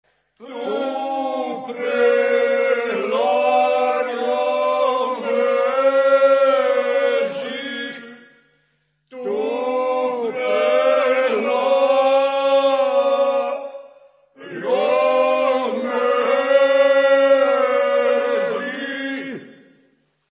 Zejanski Singers perform a traditional work song.